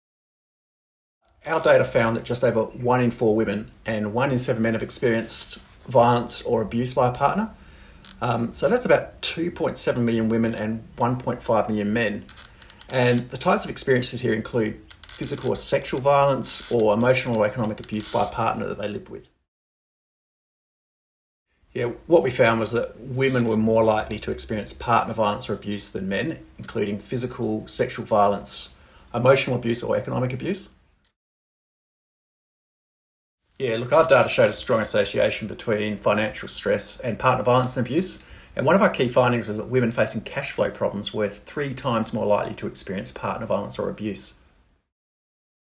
Partner violence audio grabs